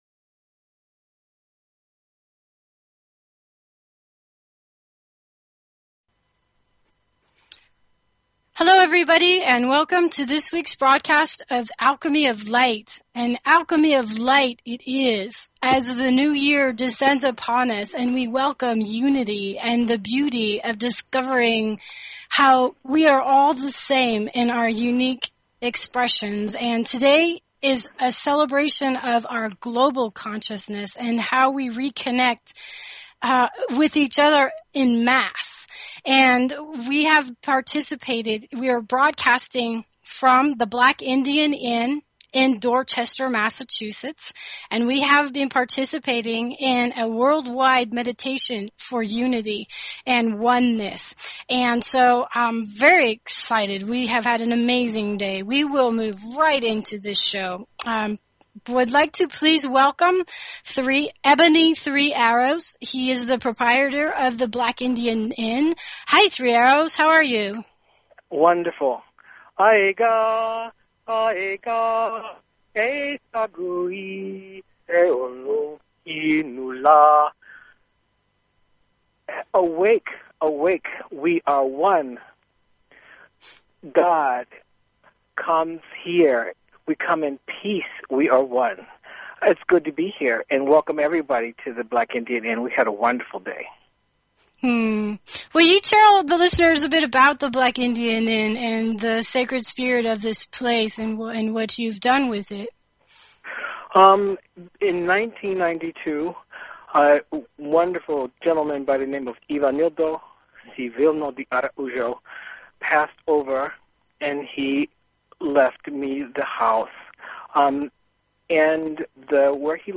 Talk Show Episode, Audio Podcast, Alchemy_of_Light and Courtesy of BBS Radio on , show guests , about , categorized as
A live broadcast from the Black Indian Inn in Dorcester, Massachussetts, where we welcomed the new year in celebration of our Oneness...1.1.11!